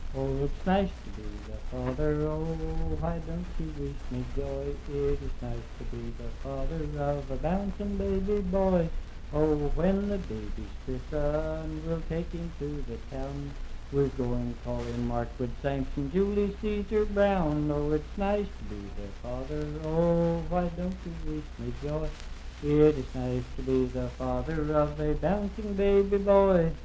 Unaccompanied vocal music
in Riverton, Pendleton County, WV.
Voice (sung)